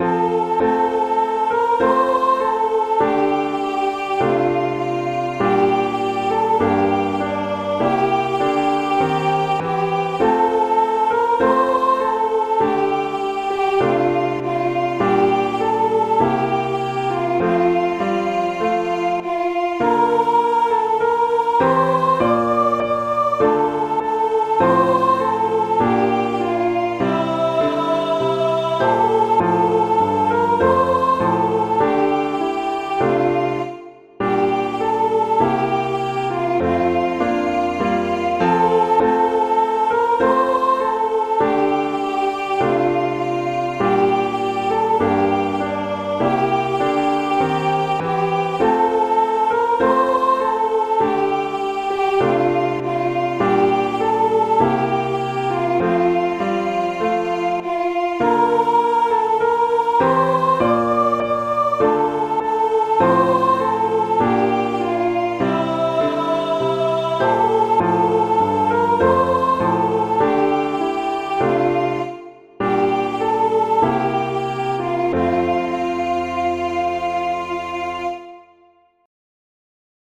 F major
♩=100 BPM